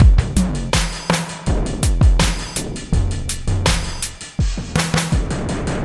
描述：在fruitity loops中创建。经过压缩，在Cool Edit中调整了EQ。
标签： 164 bpm Industrial Loops Drum Loops 1 007.46 KB wav Key : Unknown
声道立体声